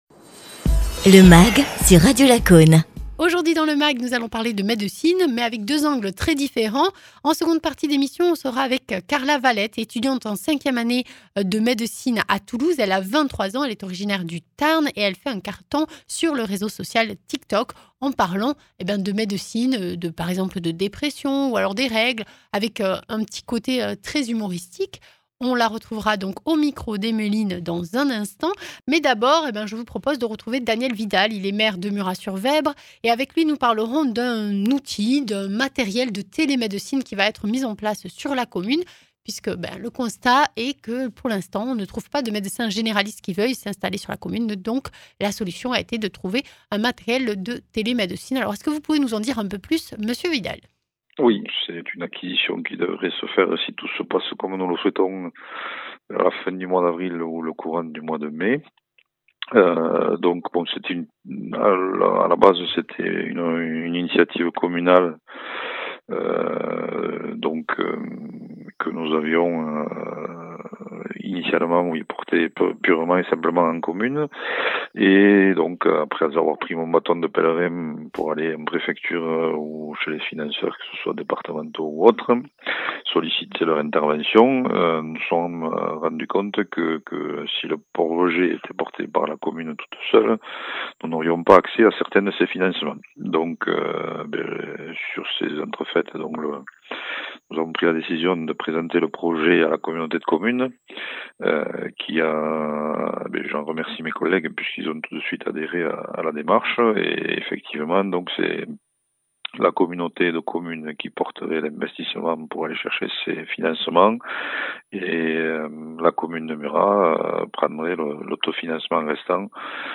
Interviews
Invité(s) : Daniel Vidal, maire de Murat-sur-Vèbre (Tarn)